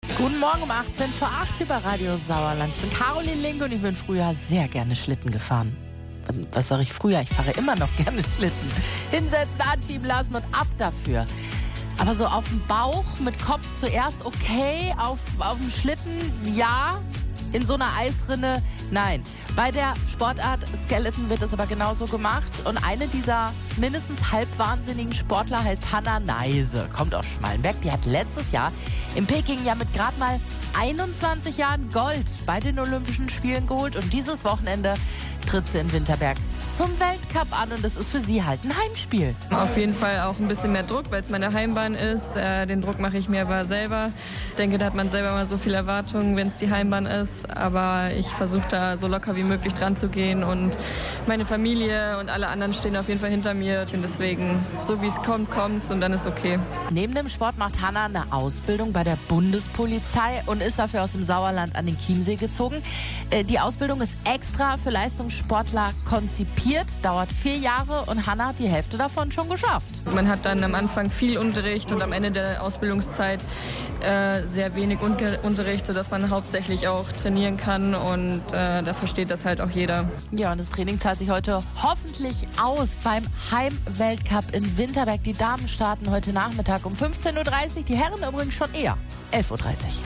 Die 22-jährige Skeleton-Pilotin und Olympiasiegerin Hannah Neise aus Schmallenberg im Interview.